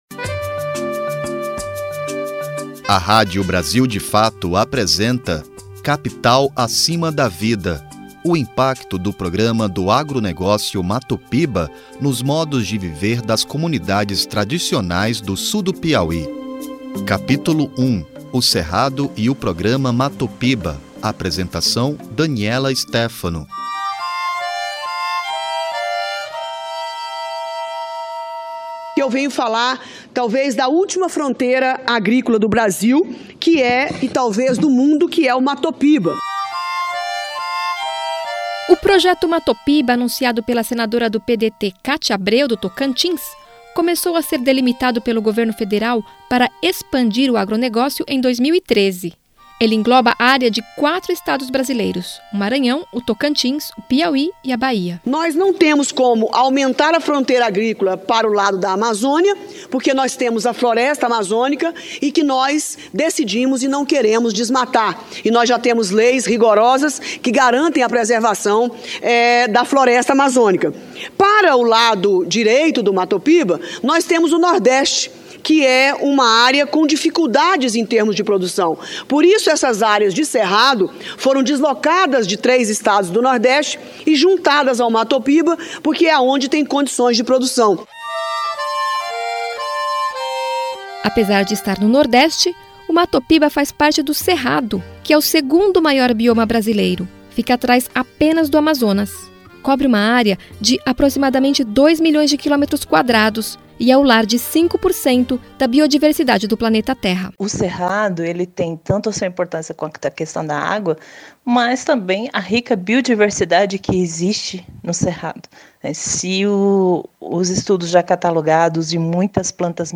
Reportagem radiofônica produzida pelo Brasil de Fato, revela o impacto do programa do agronegócio nos modos de viver das comunidades tradicionais do sul do Piauí